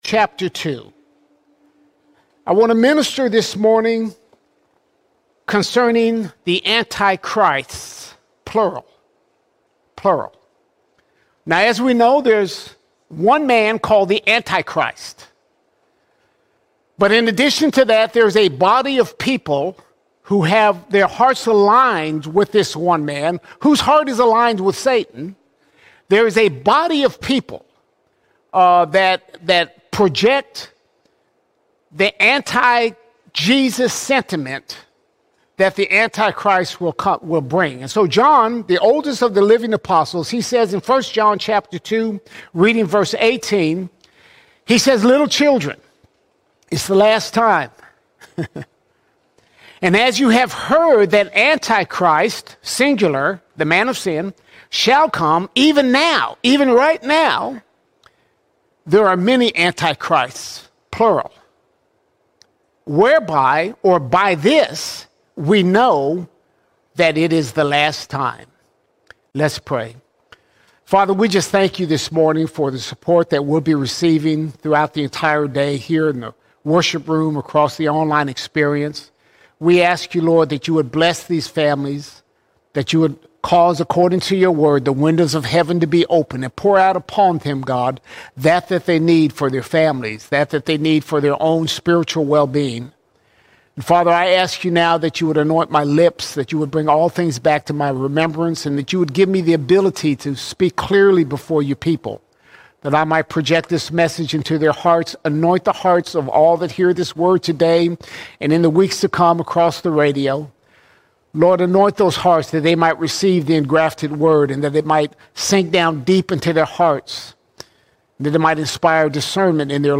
29 September 2025 Series: Sunday Sermons All Sermons Anti-Christs Anti-Christs The spirit of the Anti-Christ is already at work, paving the way through deception.